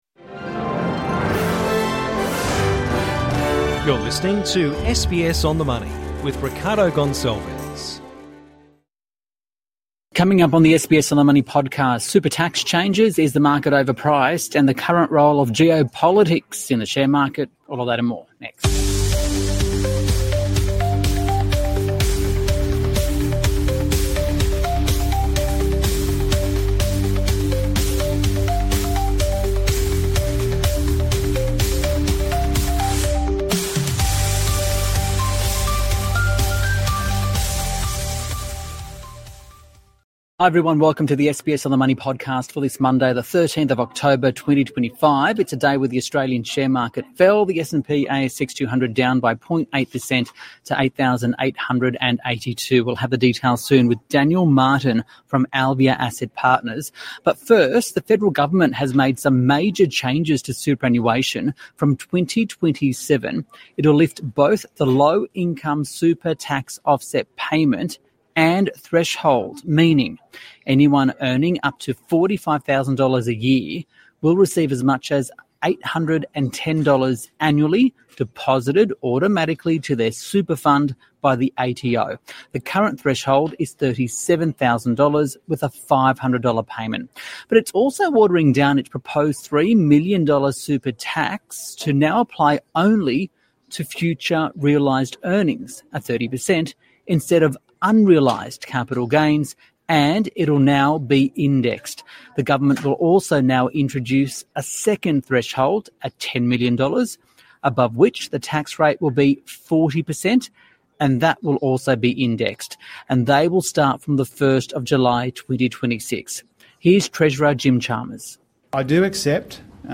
plus hear from Treasurer Jim Chalmers as he addresses changes to superannuation.